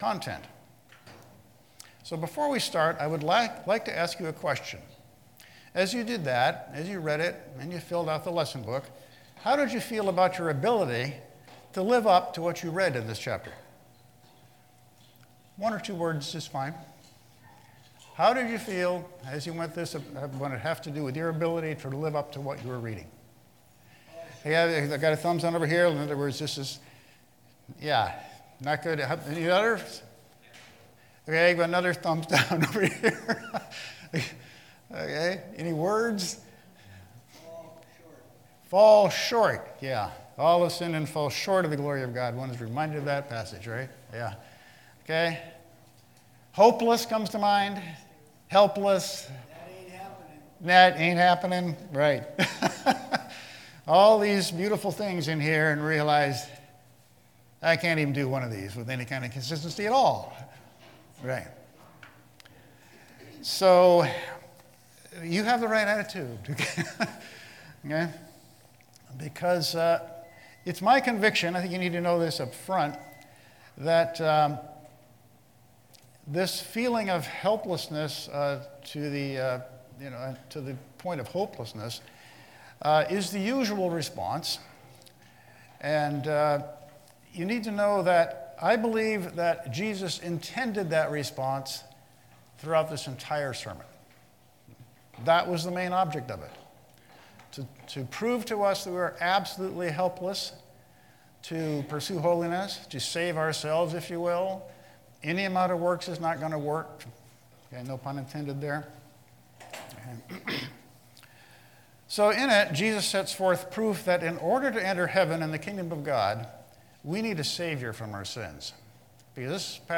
Passage: Matthew 5 Service Type: Sunday School